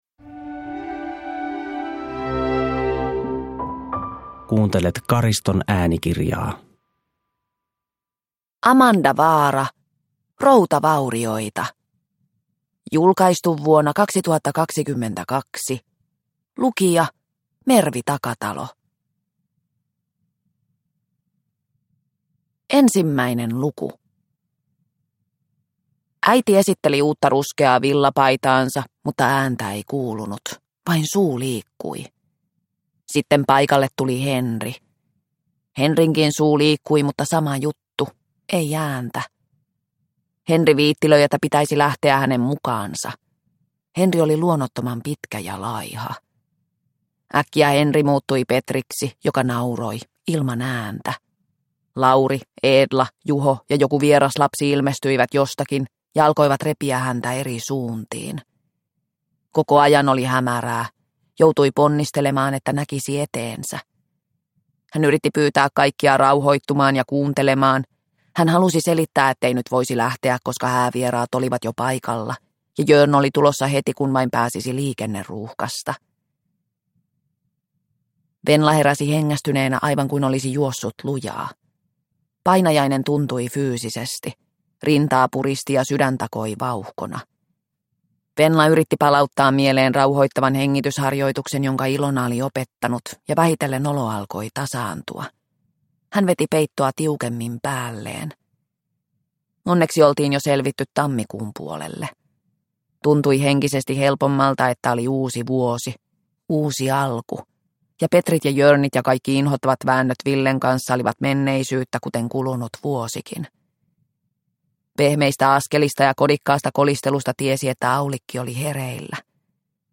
Routavaurioita – Ljudbok – Laddas ner